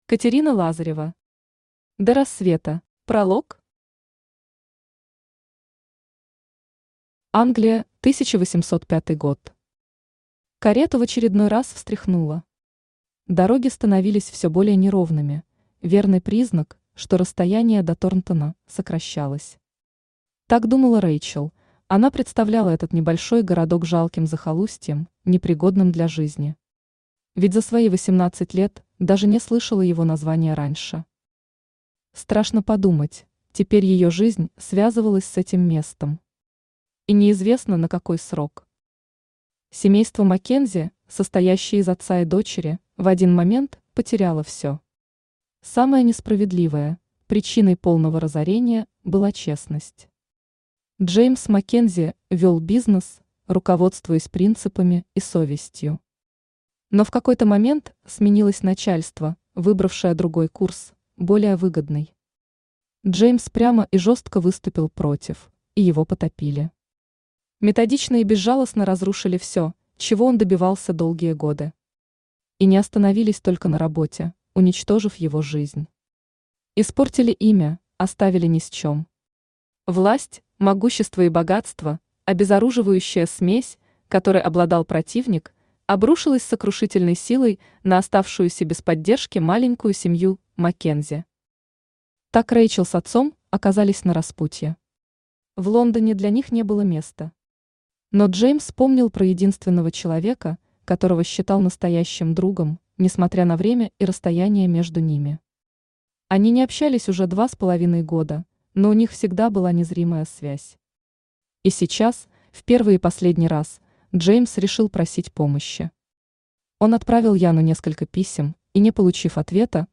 Aудиокнига До рассвета Автор Катерина Лазарева Читает аудиокнигу Авточтец ЛитРес. Прослушать и бесплатно скачать фрагмент аудиокниги